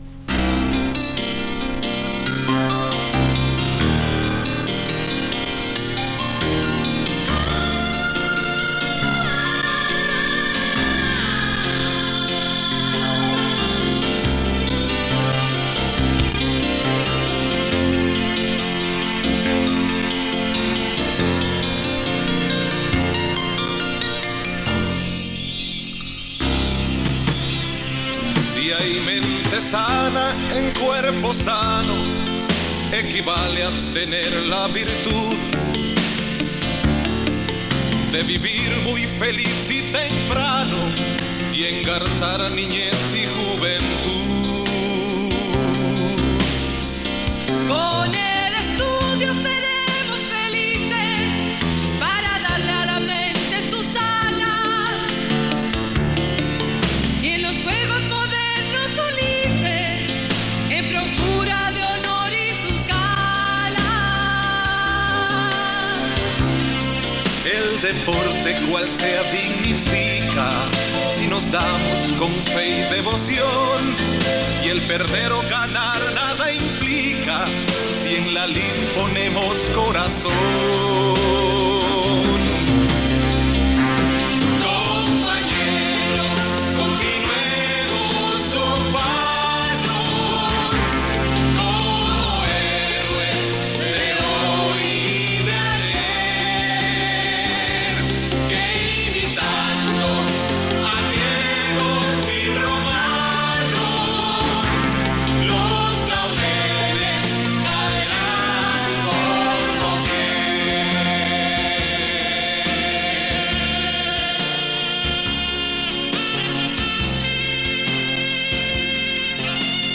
Notas: Casete de audio y digital